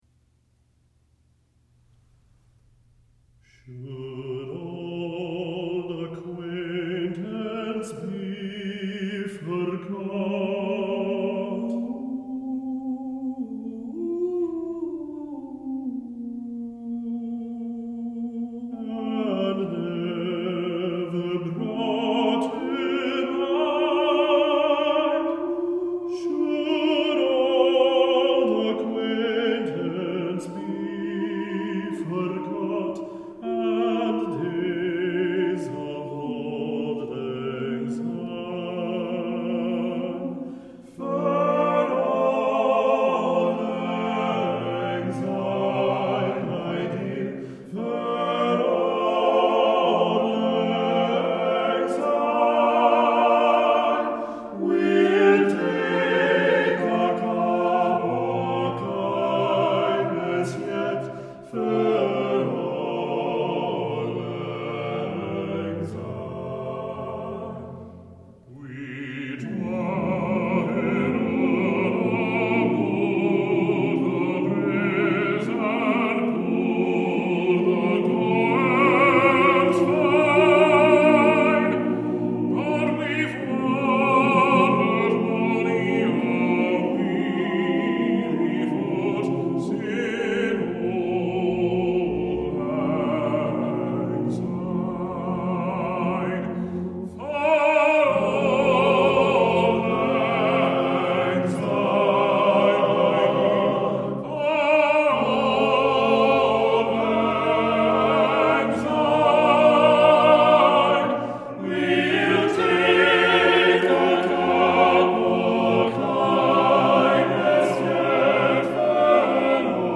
TTBB a cappella